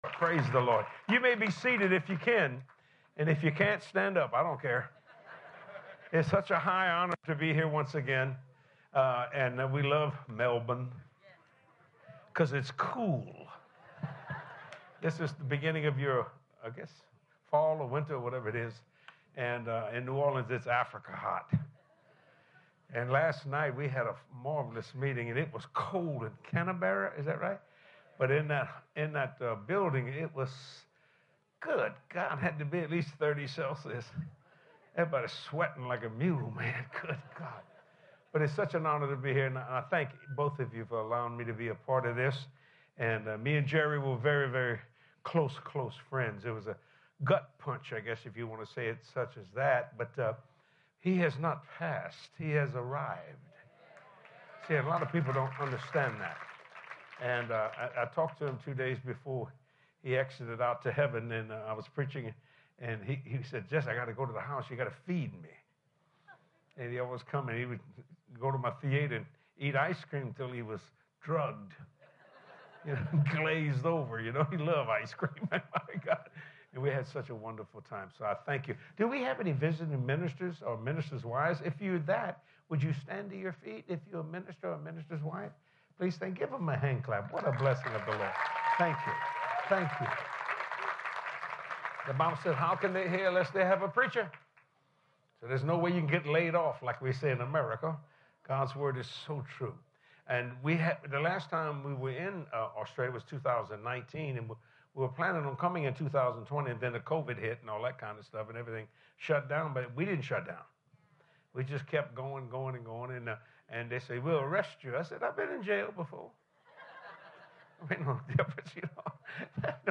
Have you been limiting God and only asking Him for the things you need? In this powerful message, Rev. Jesse Duplantis shares the revelation that nothing is too impossible for God and that time is irrelevant when pursuing the desires He has put on your heart.